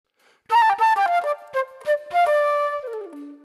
Flute Cue